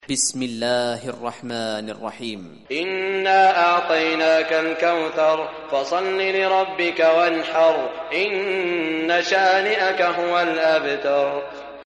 Surah Kausar Recitation by Sheikh Shuraim
Surah Kausar, listen or play online mp3 tilawat / recitation in Arabic in the beautiful voice of Sheikh Saud Shuraim.